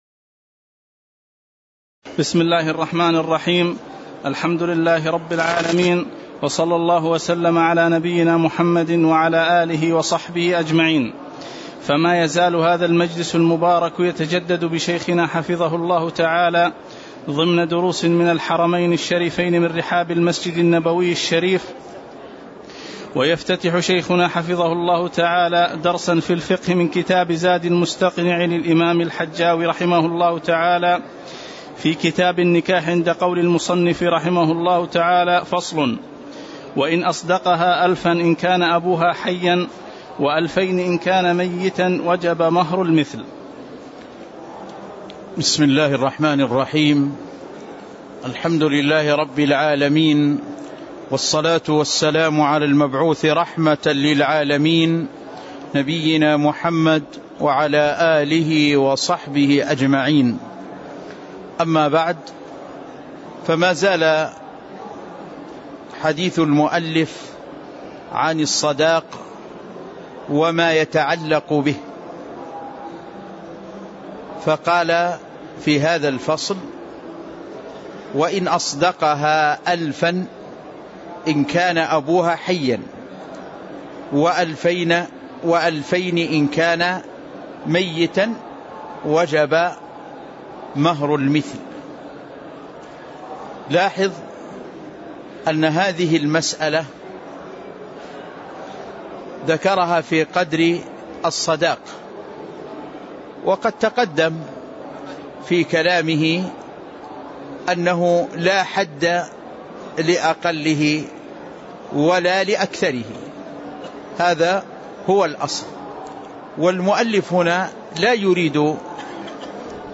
تاريخ النشر ١٩ جمادى الأولى ١٤٣٧ هـ المكان: المسجد النبوي الشيخ